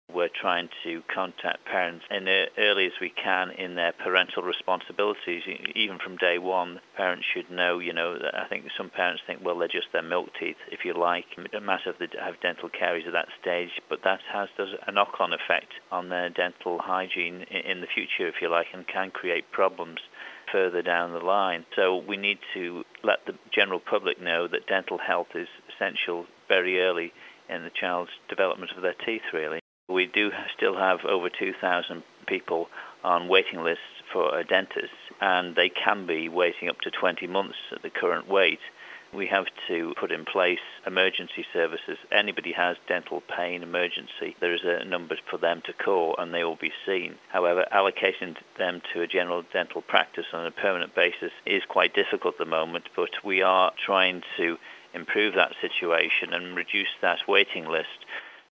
Minister for Health David Anderson told 3FM how the new campaign would be persuading parents to take responsibilty for their children's dental care but finding an NHS dentist is still a problem